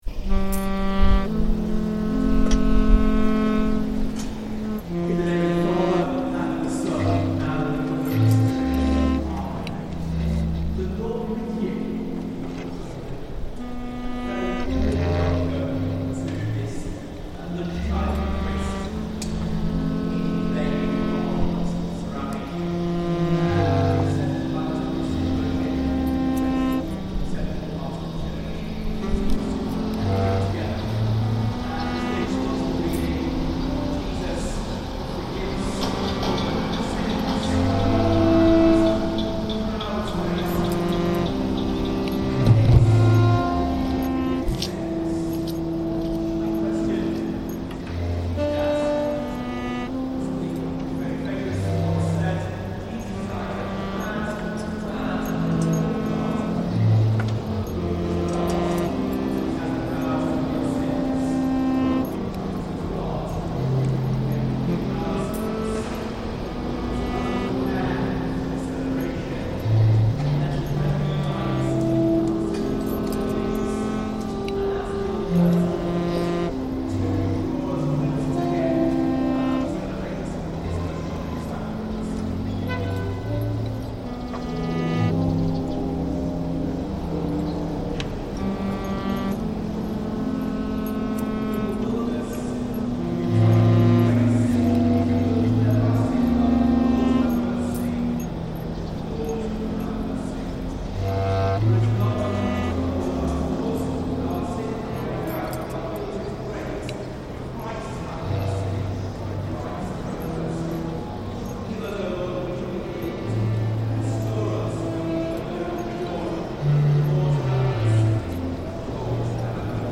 Westminster Abbey communion service reimagined